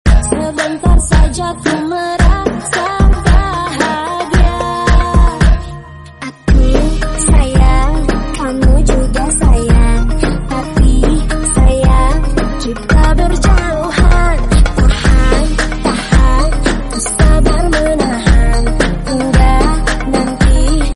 egyption remix